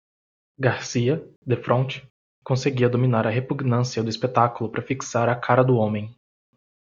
Pronounced as (IPA) /fikˈsa(ʁ)/